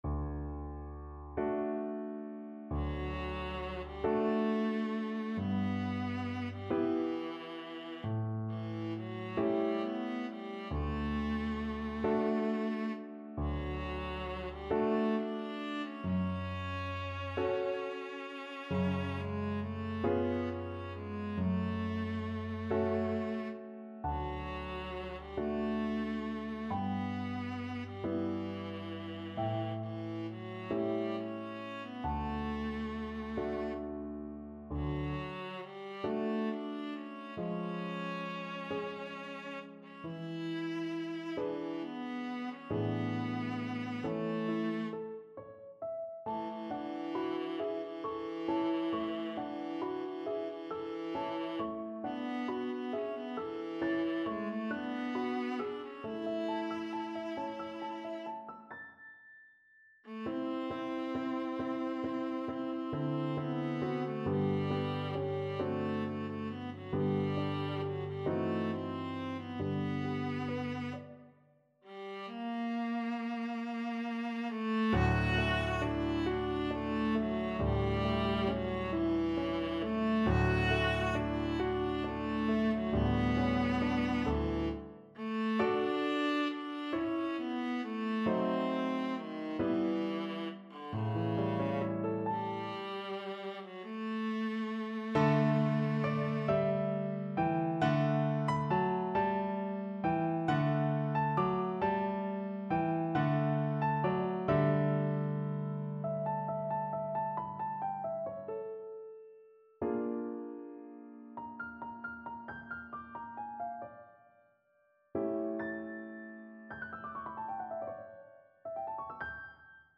Viola
6/8 (View more 6/8 Music)
D major (Sounding Pitch) (View more D major Music for Viola )
Andante .=45
Classical (View more Classical Viola Music)